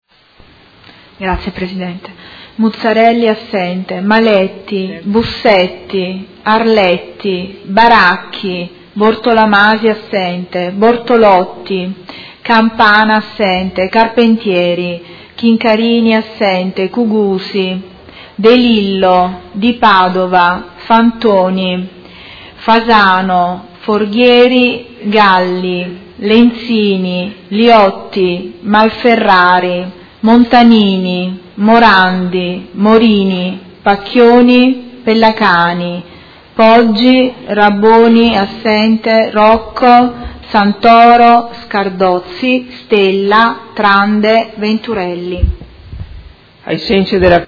Seduta del 26/03/2018 Appello.
Segretaria